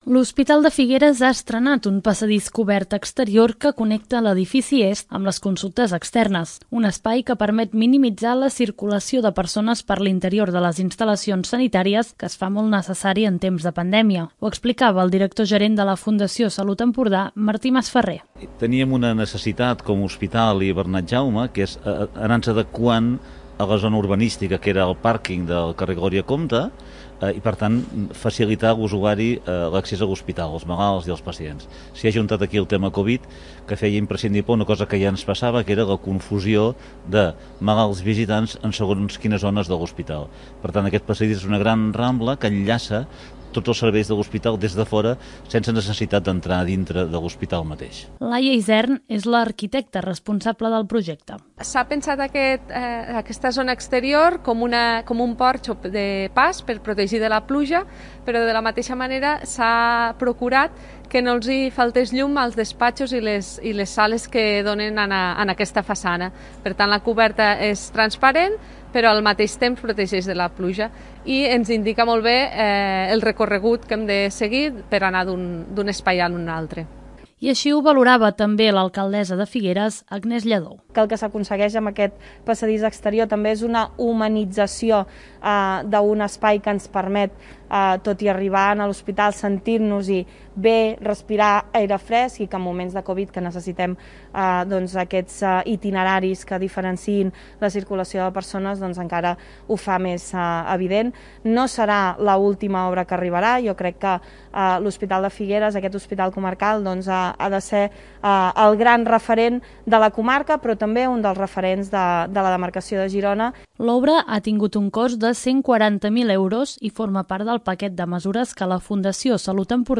Així ho valorava, també, l'alcaldessa de Figueres, Agnès Lladó.